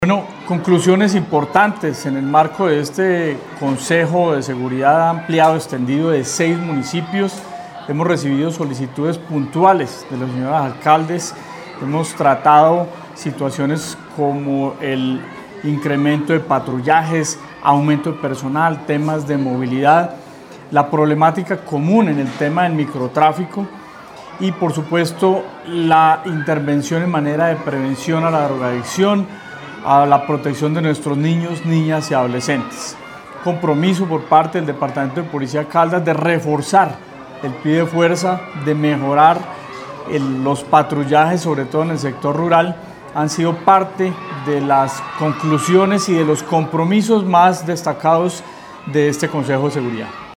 Coronel Alex Durán Santos, comandante Departamento de Policía Caldas
Coronel-Alex-Duran-Santos-comandante-Departamento-de-Policia-Caldas-1.mp3